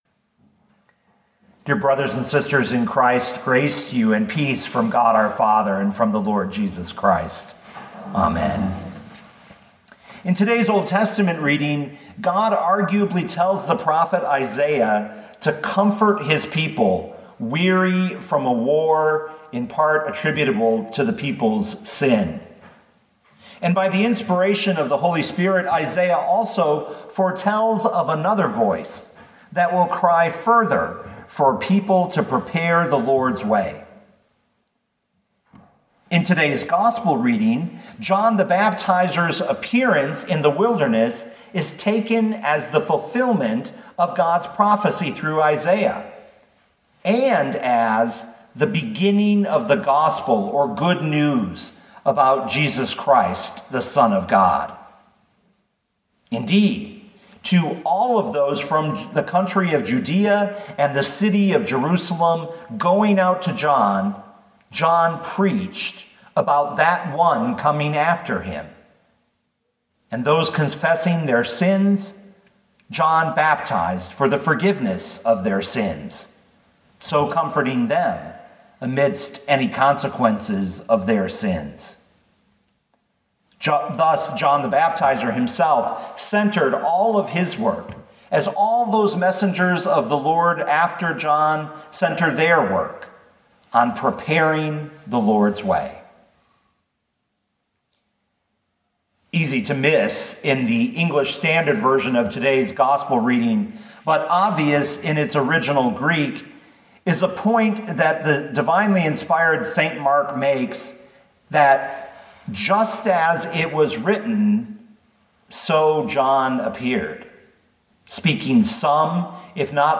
2020 Mark 1:1-8 Listen to the sermon with the player below, or, download the audio.